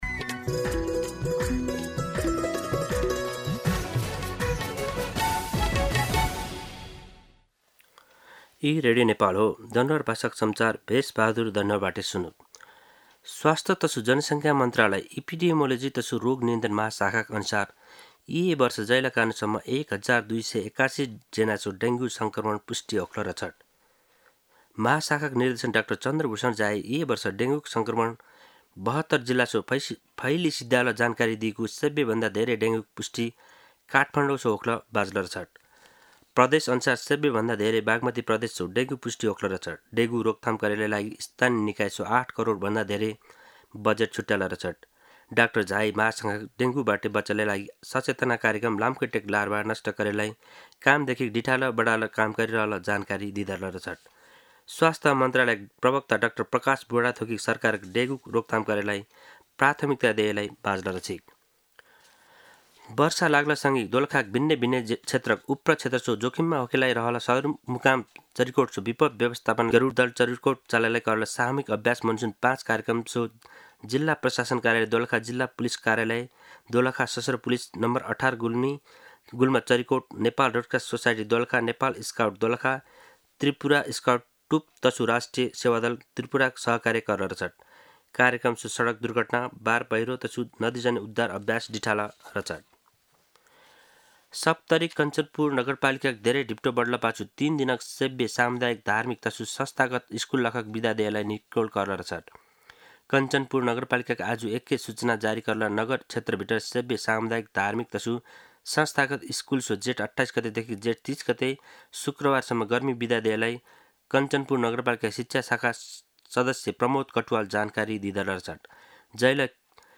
दनुवार भाषामा समाचार : २८ जेठ , २०८२
Danuwar-News-2-28.mp3